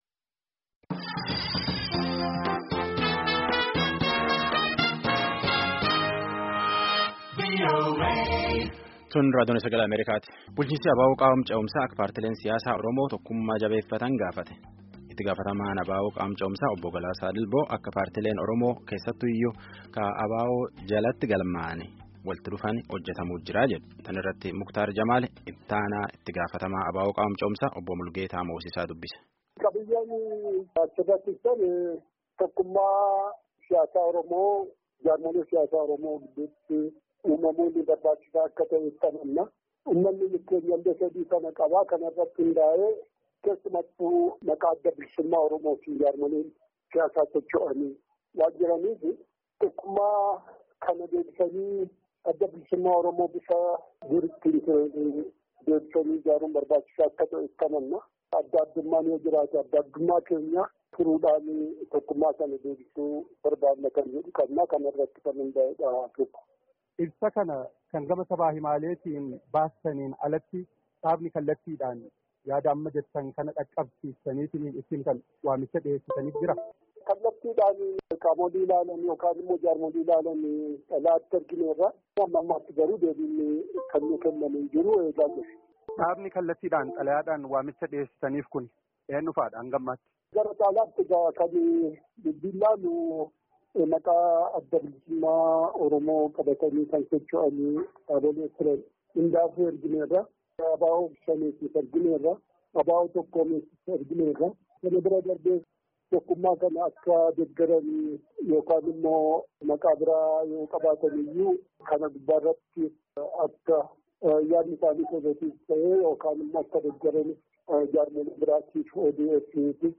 Gaaffii fi deebii